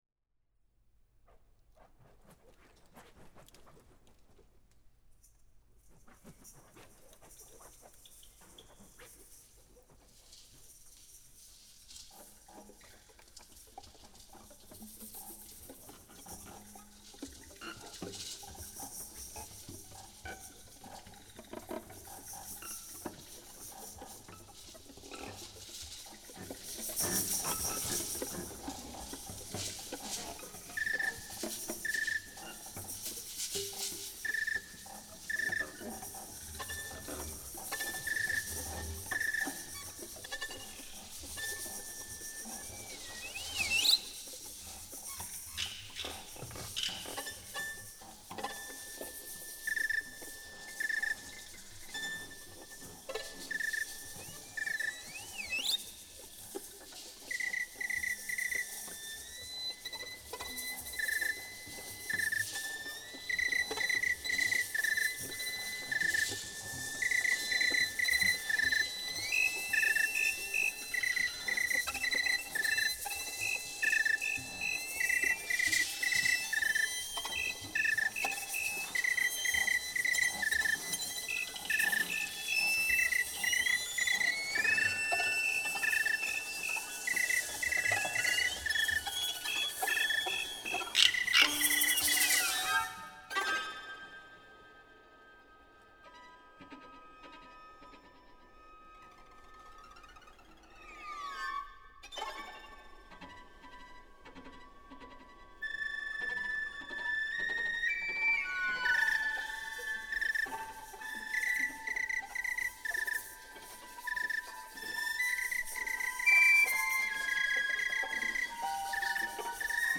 the_insect_concerto.mp3